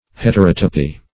Heterotopism \Het`er*ot"o*pism\, Heterotopy \Het`er*ot"o*py\, n.